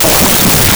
rewind.wav